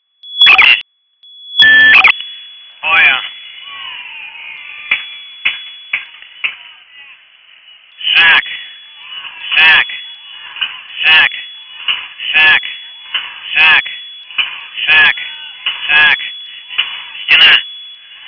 Сигнал после снятия инверсии